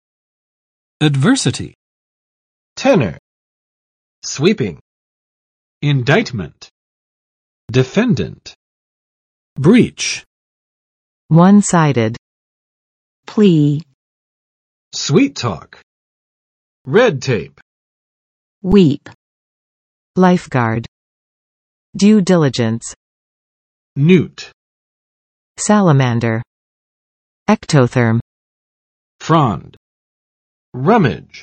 [ədˋvɝsətɪ] n. 逆境；厄运